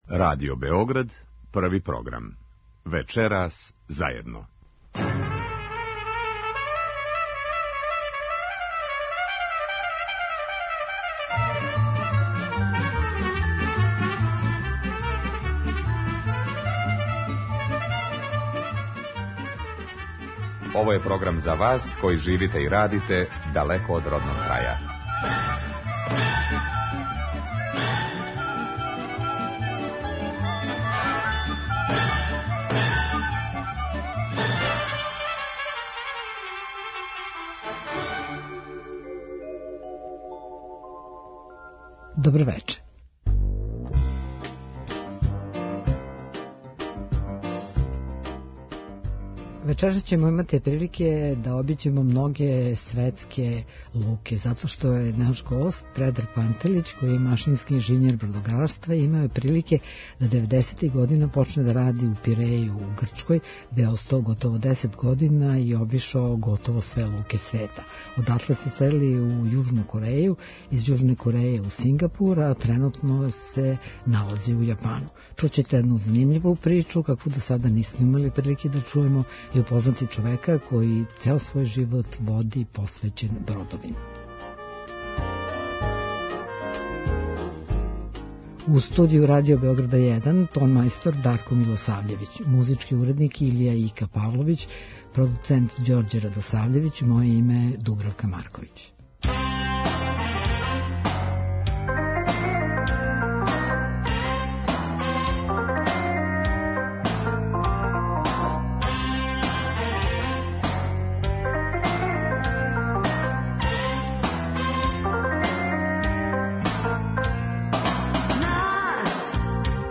Емисија магазинског типа која се емитује сваког петка од 21 час.